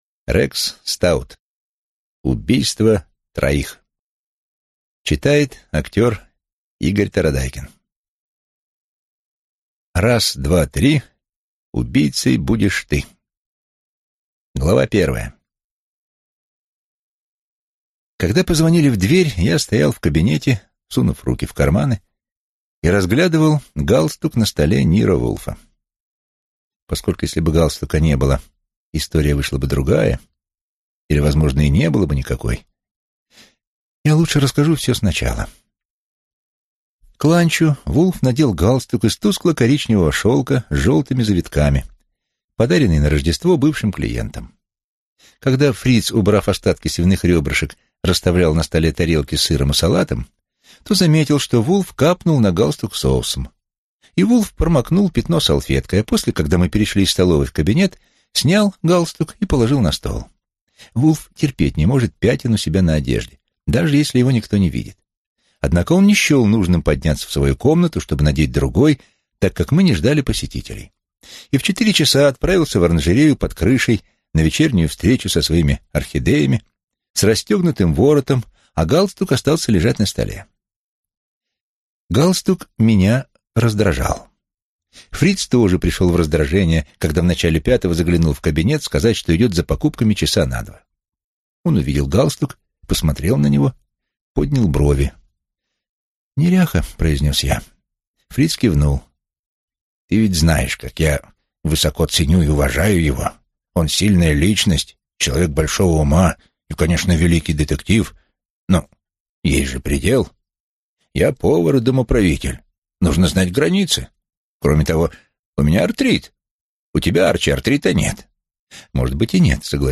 Аудиокнига Убийство троих | Библиотека аудиокниг